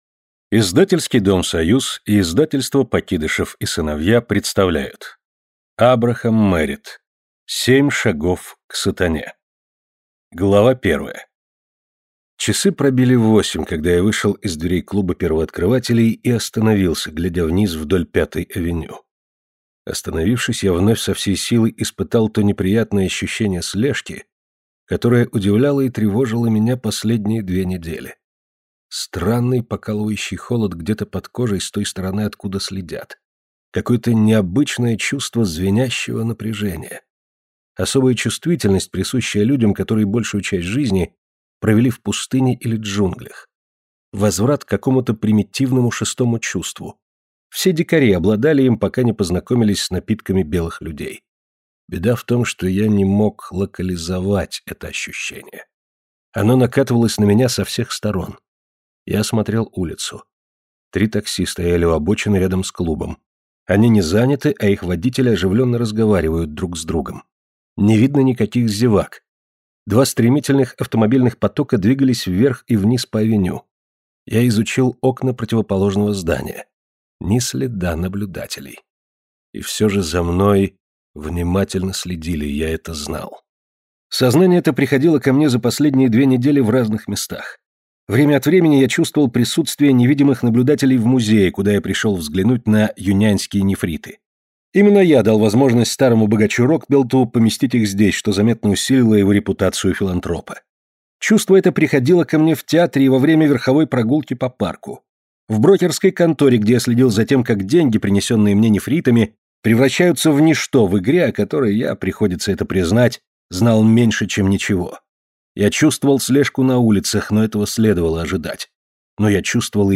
Аудиокнига Семь шагов к сатане | Библиотека аудиокниг